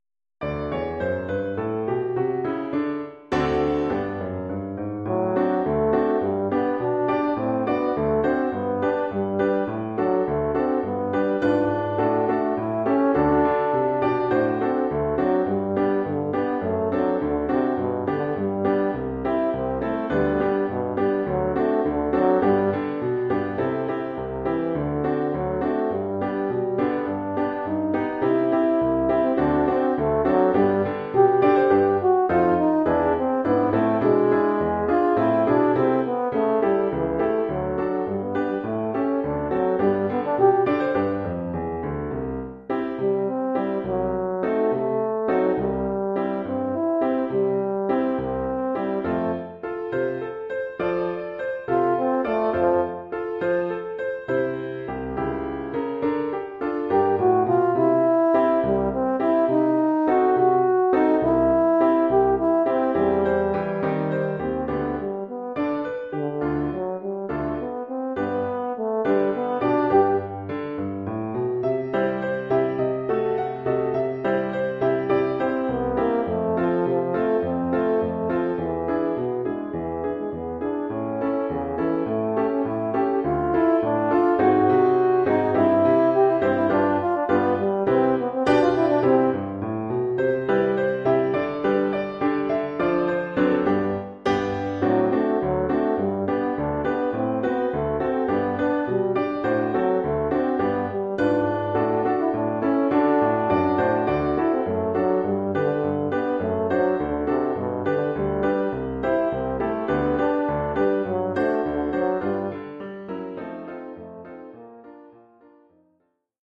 Oeuvre pour cor d’harmonie et piano.